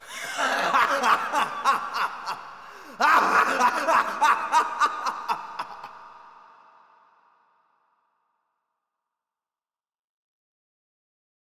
insane laughter man reverb
Category 🤣 Funny
evil insane laugh laughter man sound effect free sound royalty free Funny